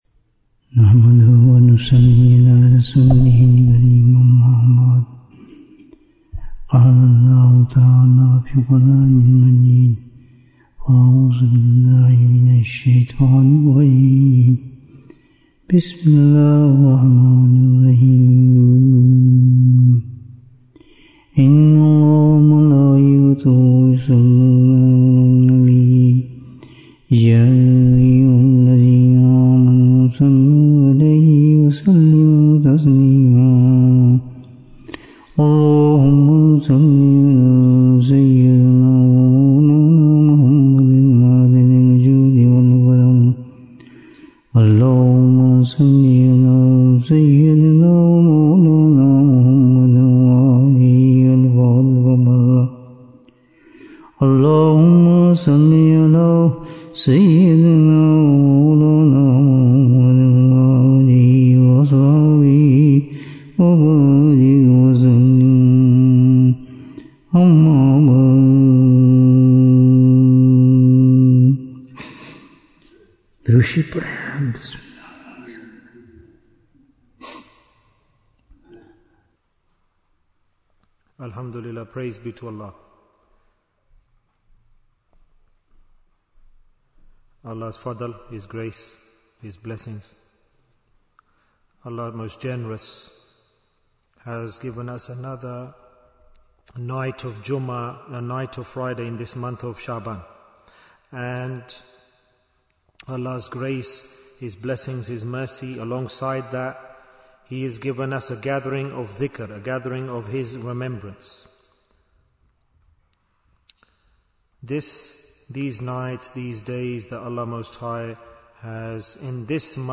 How Can One Become Thankful? Bayan, 109 minutes29th January, 2026